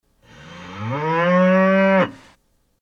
Kravička
Kravička nám dává mlíčko a zdraví nás nahlas svým: „Bůů!“
krava.mp3